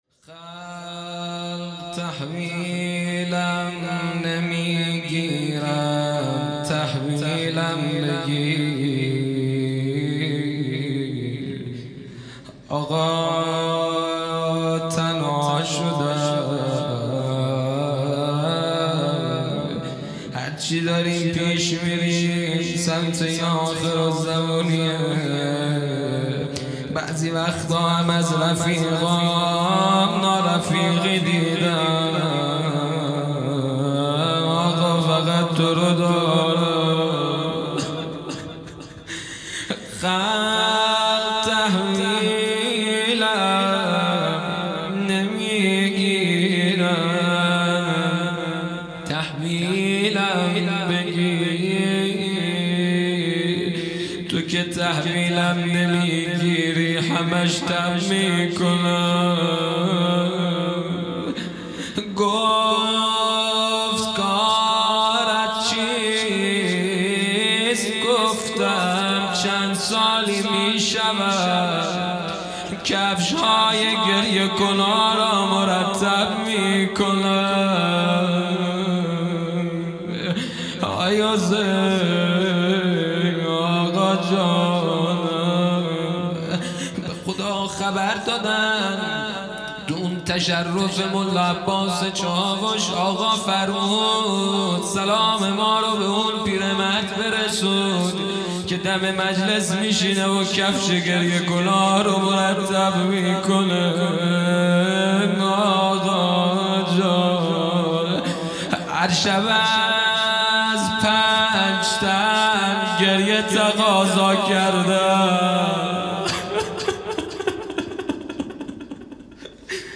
خیمه گاه - هیئت دانشجویی فاطمیون دانشگاه یزد - روضه
جلسه هفتگی ۲۰ آذر ۹۷